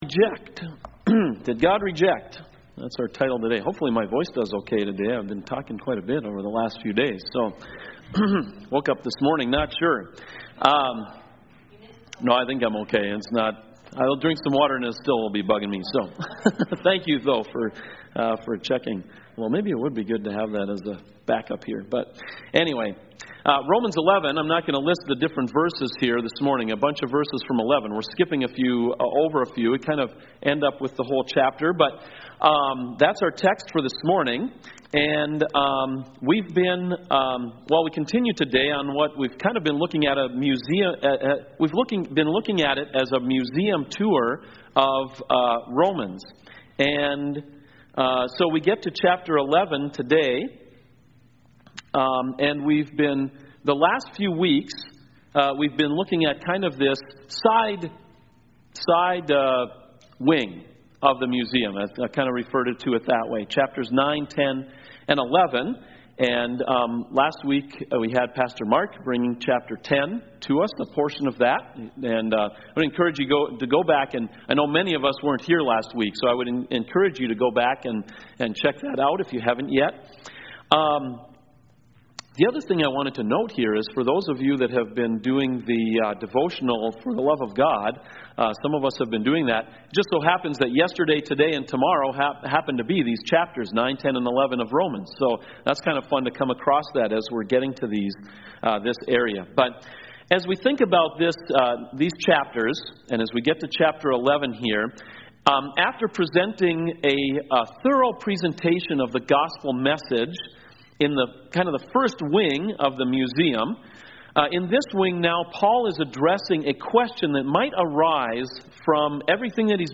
CoJ Sermons Did God Reject?